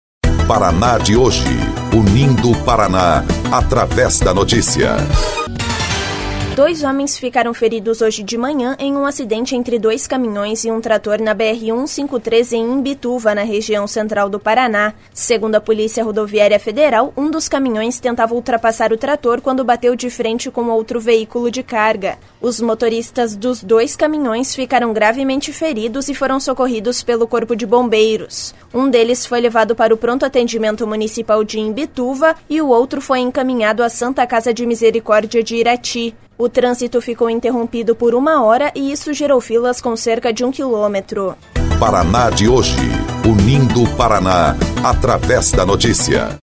BOLETIM - Acidente entre caminhões e trator deixa feridos na BR-153